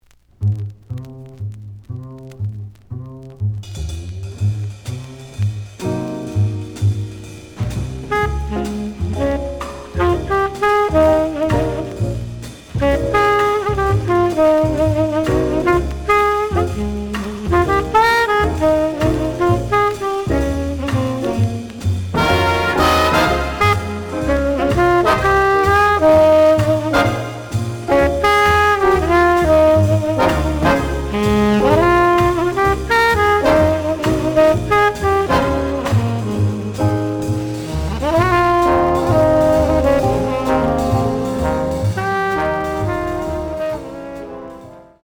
The audio sample is recorded from the actual item.
●Genre: Modern Jazz, Cool Jazz